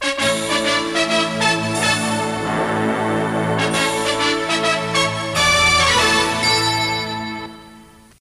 Sintonia de les Emissores Municipals de Catalunya
FM